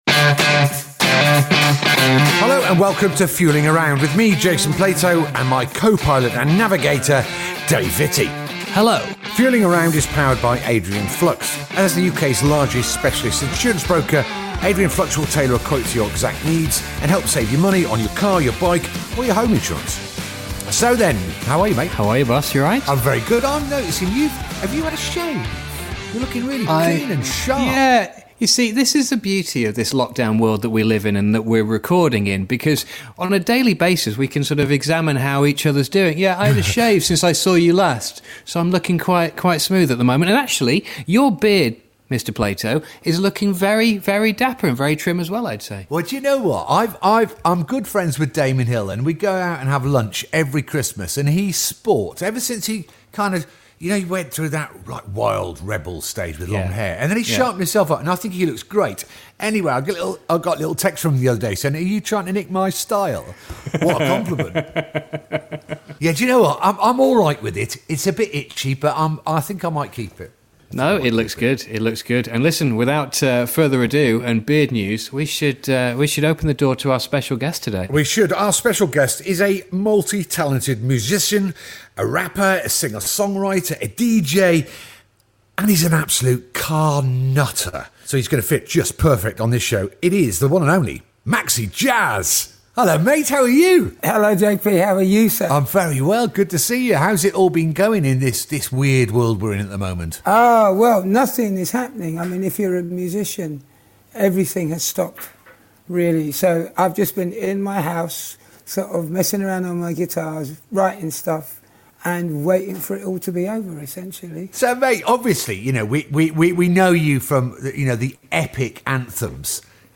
This episode was recorded remotely.